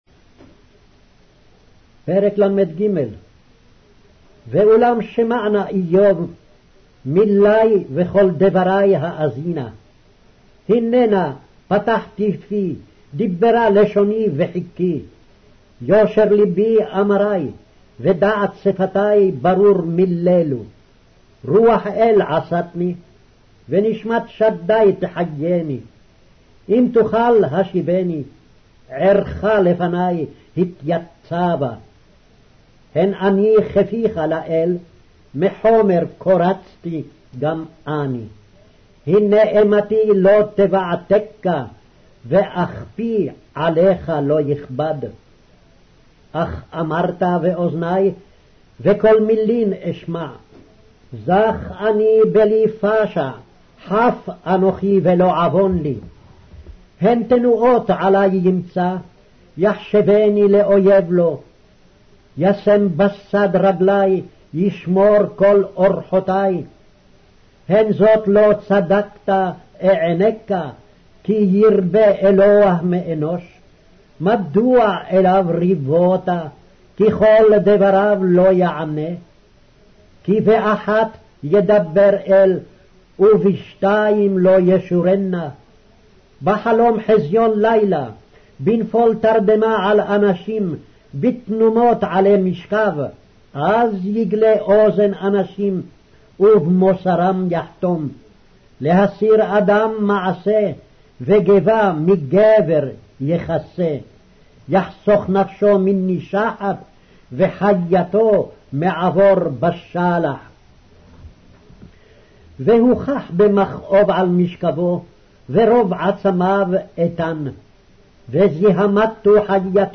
Hebrew Audio Bible - Job 21 in Nlt bible version